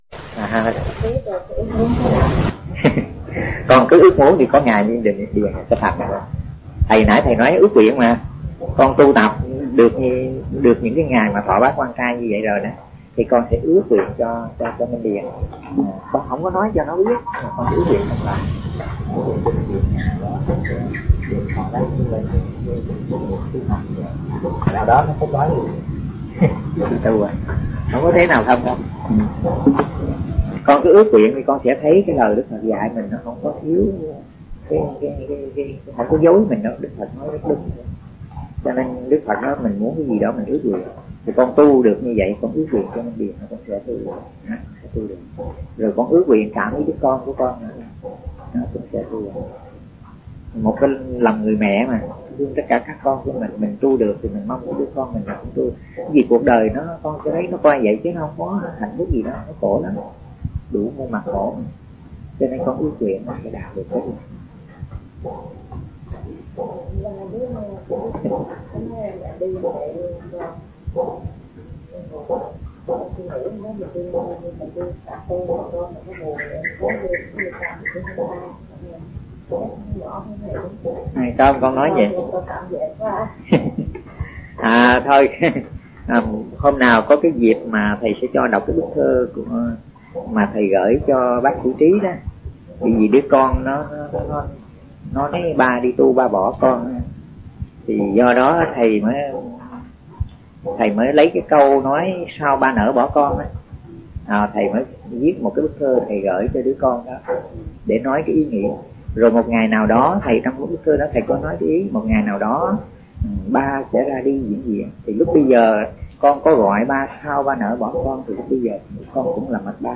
Người nghe: Phật tử